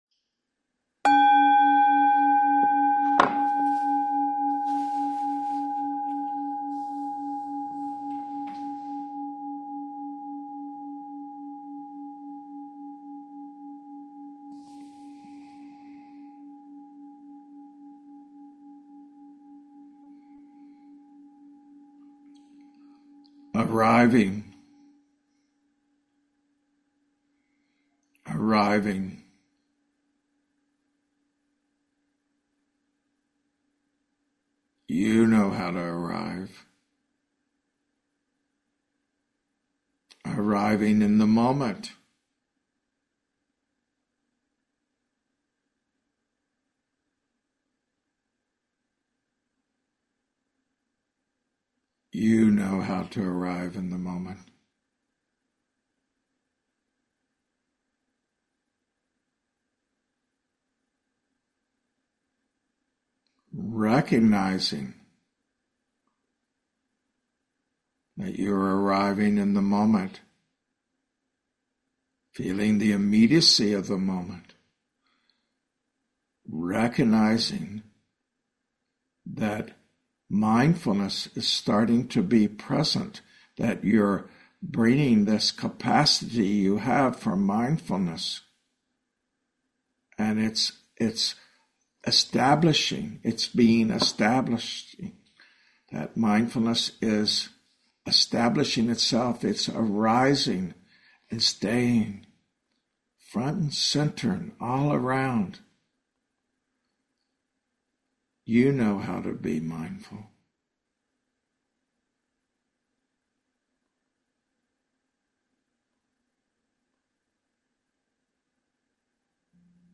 Talks
Guided Meditation: Integrity, Humility, and Inner Dignity